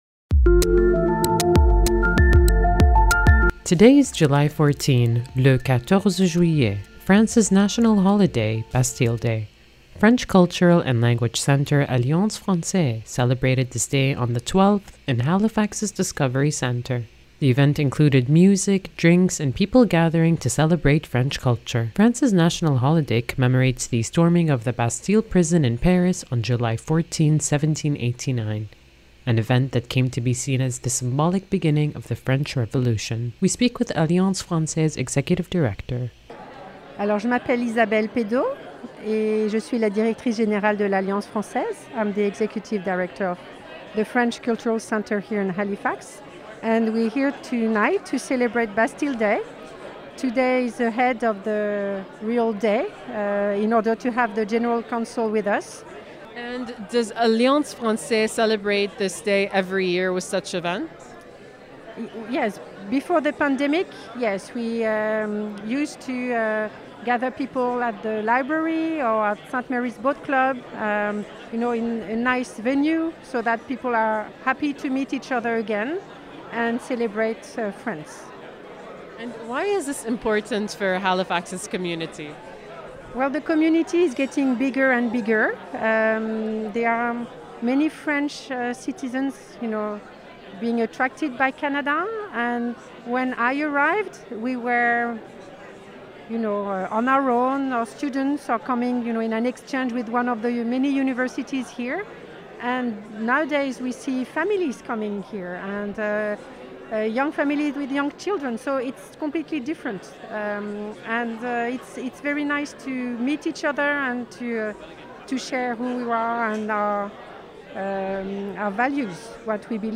On July 12 a ceremony was held at 7 p.m. at Halifax's Discovery Centre to mark France's national holiday a few days early while celebrating the Franco-Acadian friendship.